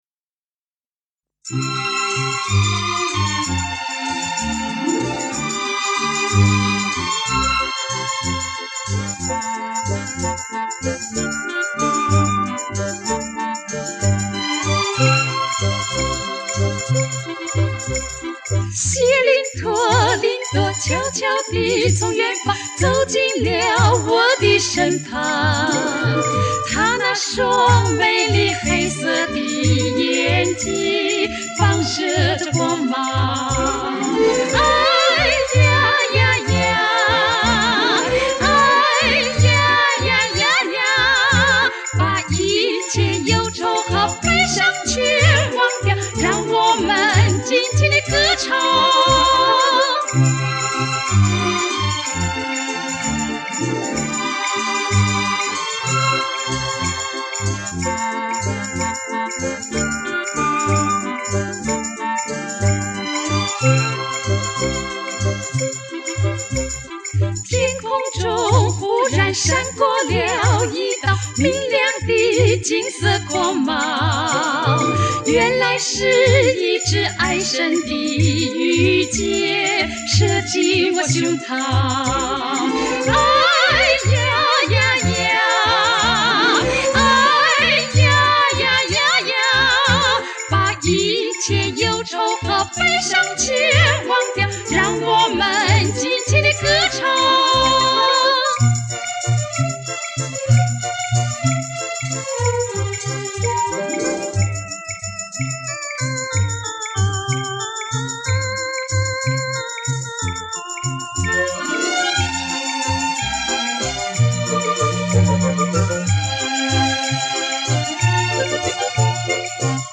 墨西哥民歌
前南斯拉夫民歌
叙利亚民歌